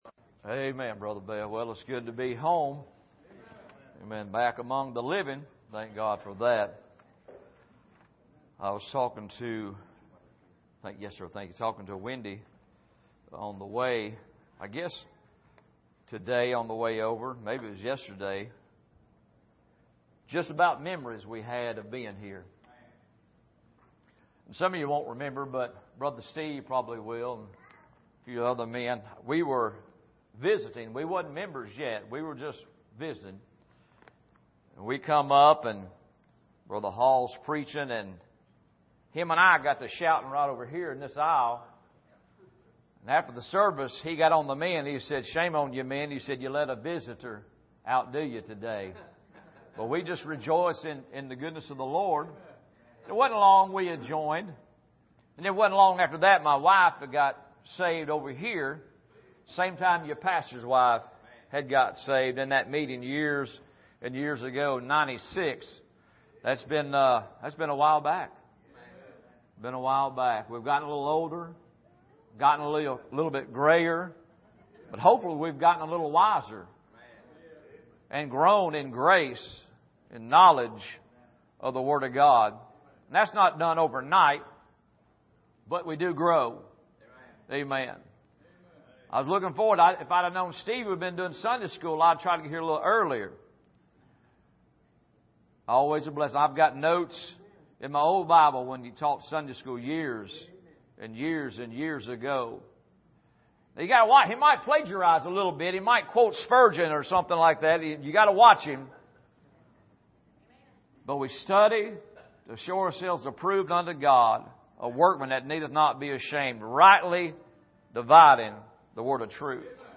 Sermon Archive
Here is an archive of messages preached at the Island Ford Baptist Church.